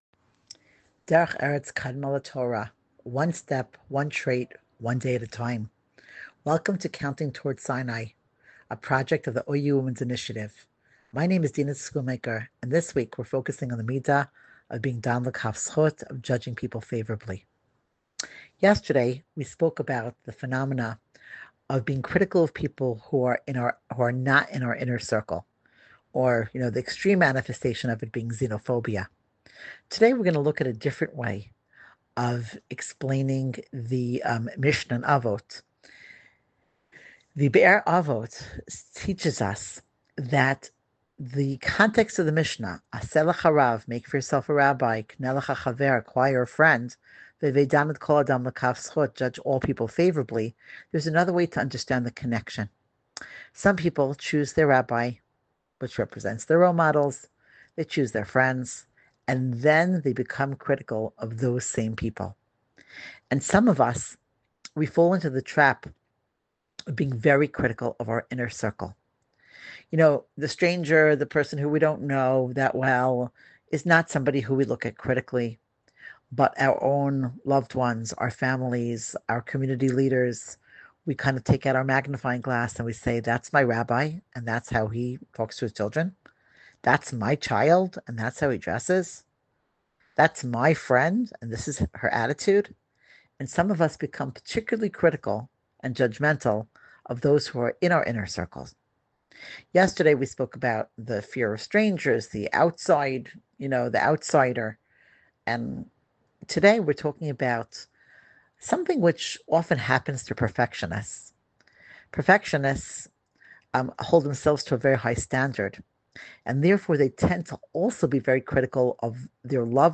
Join thousands of women worldwide working on a new middah each week during Sefirat HaOmer. Listen to the Counting Towards Sinai audio series featuring short daily inspirational thoughts presented by the educator of the week.